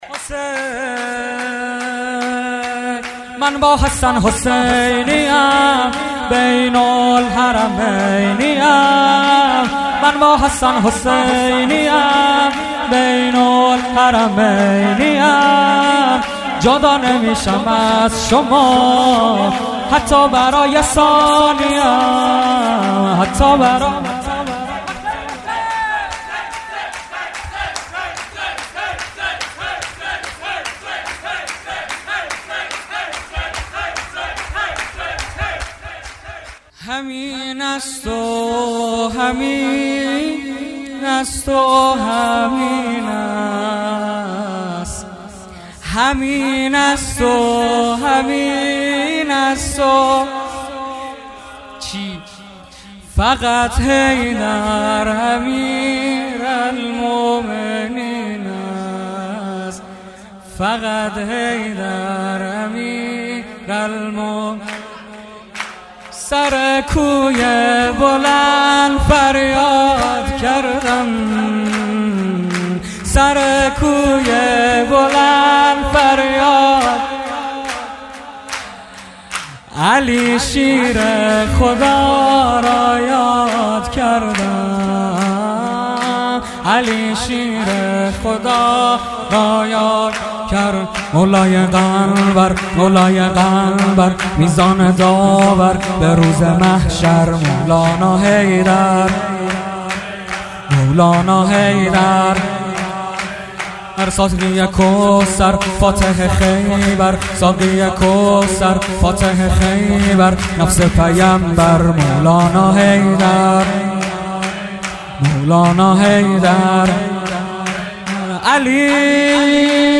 ولادت امام کاظم 97